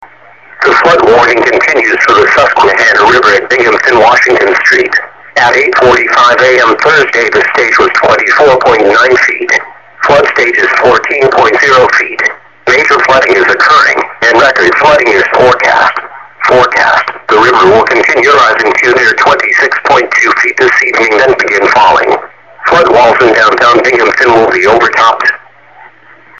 Flood Warning - Susquehanna River at Binghamton Washington Street